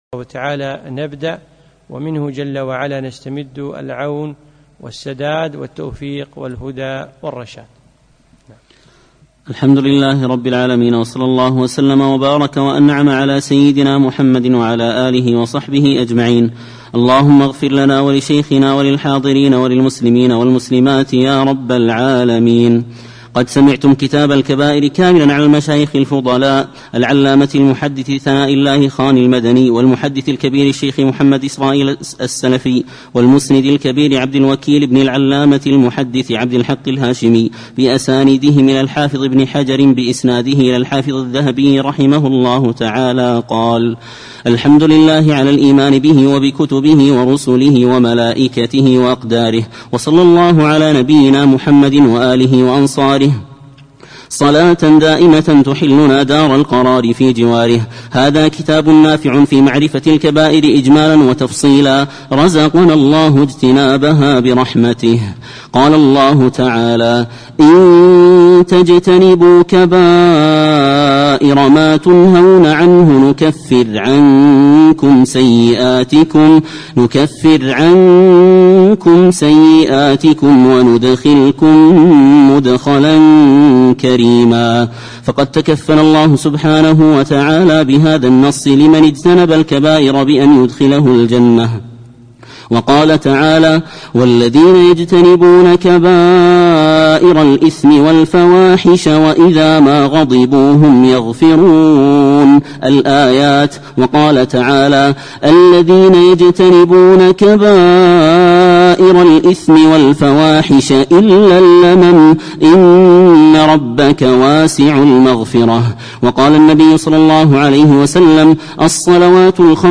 يوم الأحد 16 ذو العقدة 1436هـ 3 8 2015م في مسجد عائشة المحري المسايل